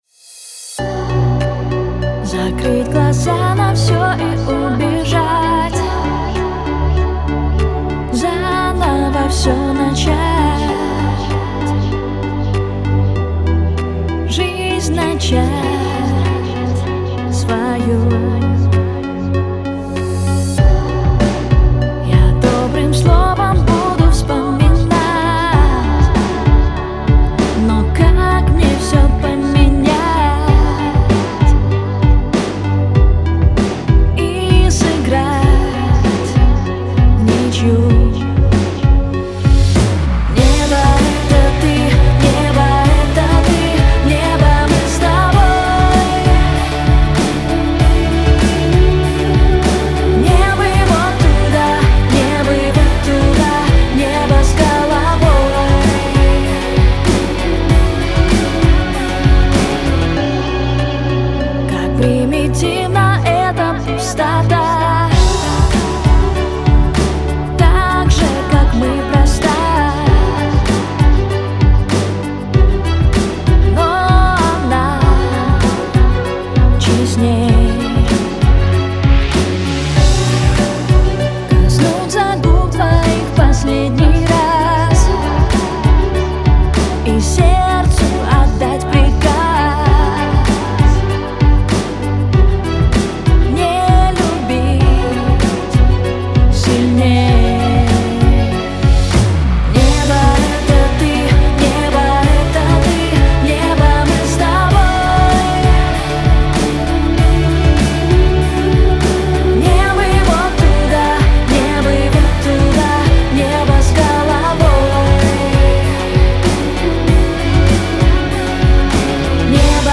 Суициидальненько ))